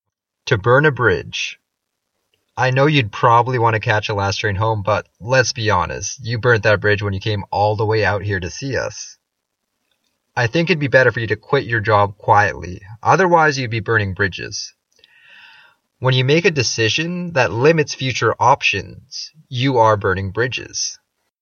英語ネイティブによる発音は下記のリンクをクリックしてください。
toburnabridge.mp3